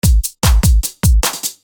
沙卡节拍
Tag: 150 bpm Electronic Loops Drum Loops 275.80 KB wav Key : Unknown